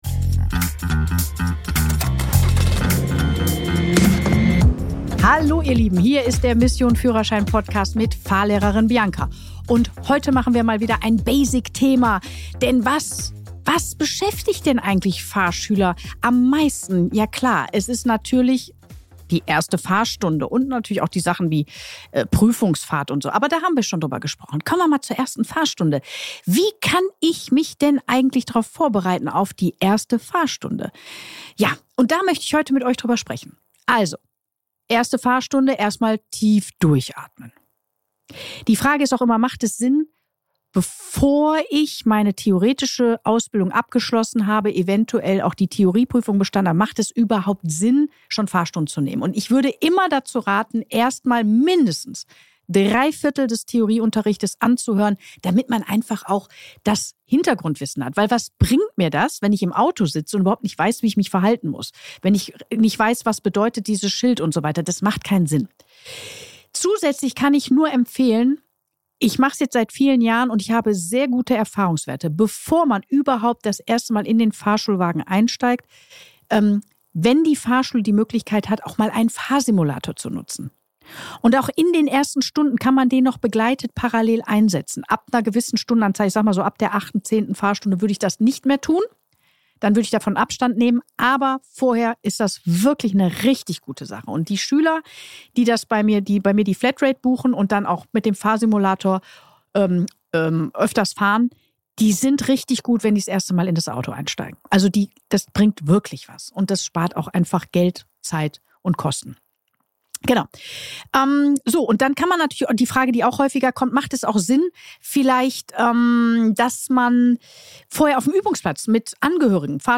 In dieser Solo-Folge von Mission Führerschein spreche ich über das Thema, das fast alle Fahrschüler am meisten beschäftigt: die erste Fahrstunde!